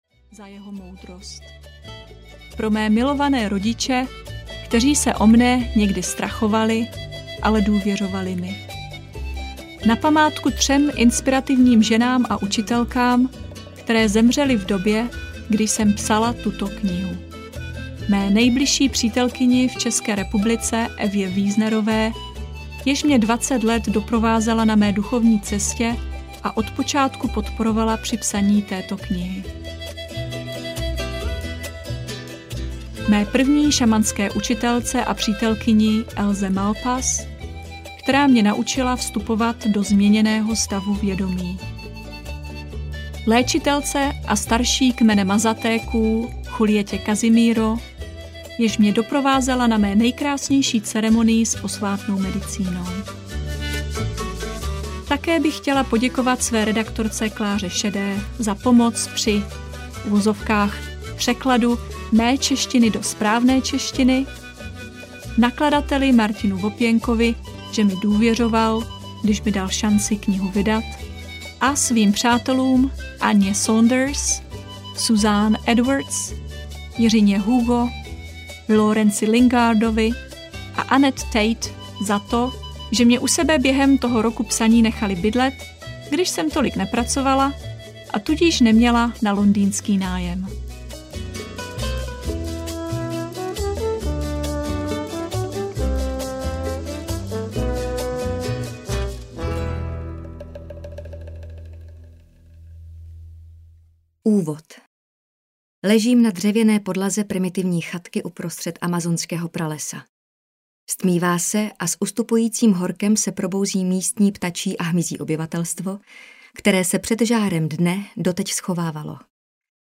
Myslet srdcem audiokniha
Ukázka z knihy
Nyní si ji tedy můžete poslechnout v nezapomenutelném přednesu Jany Plodkové.
• InterpretJana Plodková